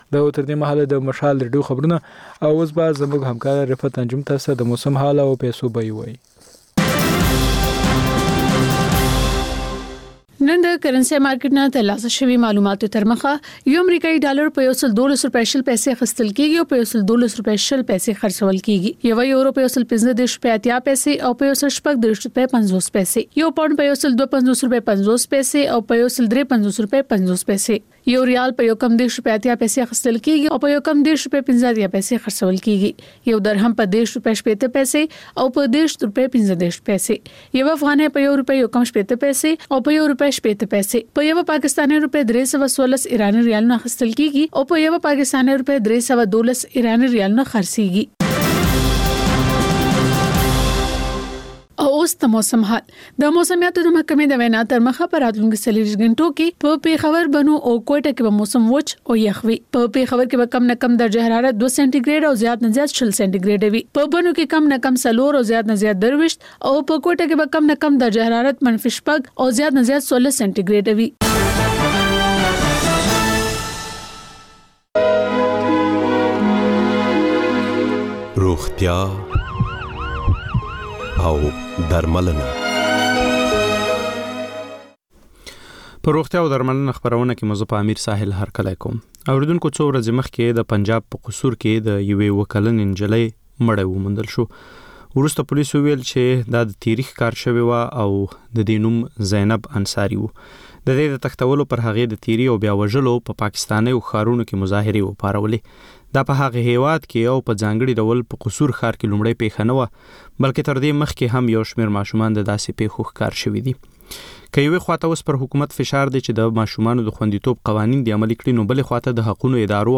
په روغتیا او درملنه خپرونه کې یو ډاکتر د یوې ځانګړې ناروغۍ په اړه د خلکو پوښتنو ته د ټیلي فون له لارې ځواب وايي.